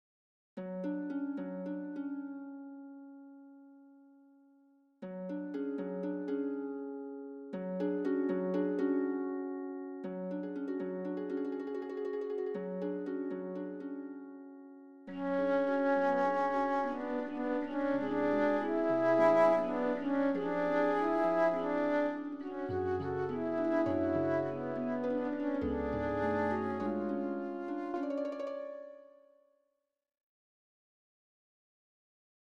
Charakter des Stückes: Taktwechsel
Tonart(en): A-Dur